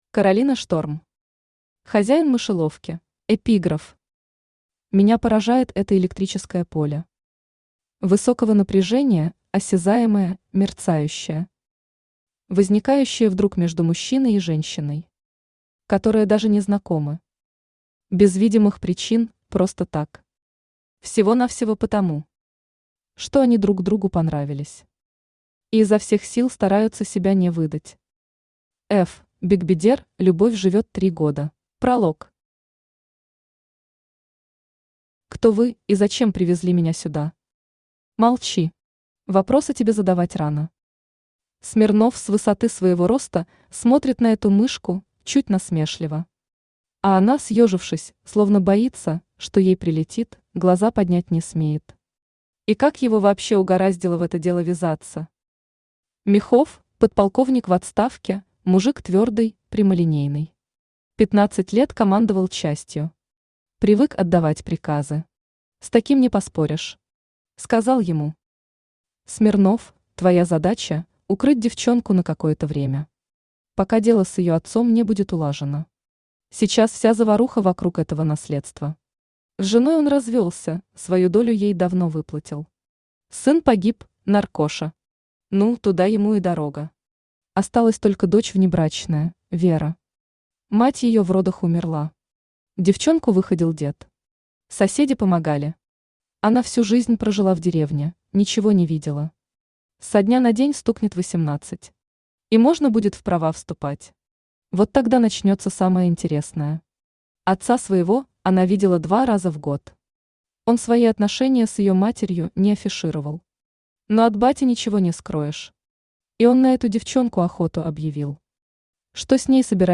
Аудиокнига Хозяин мышеловки | Библиотека аудиокниг
Aудиокнига Хозяин мышеловки Автор Каролина Шторм Читает аудиокнигу Авточтец ЛитРес.